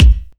KICK37.wav